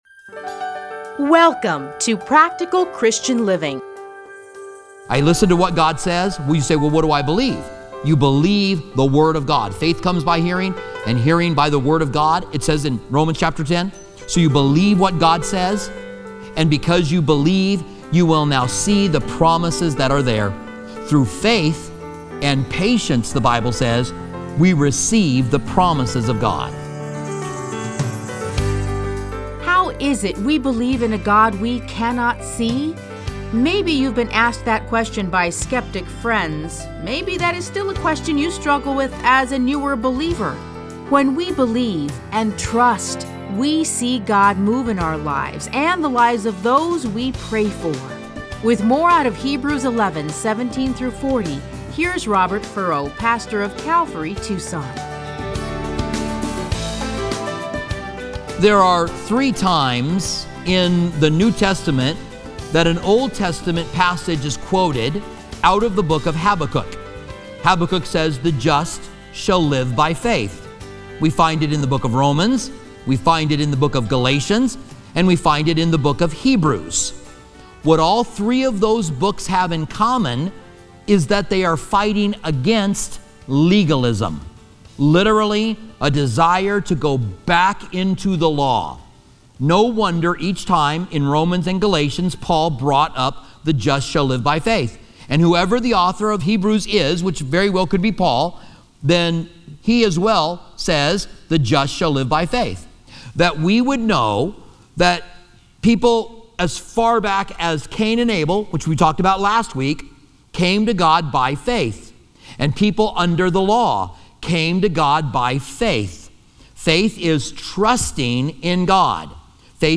Listen to a teaching from Hebrews 11:17-40.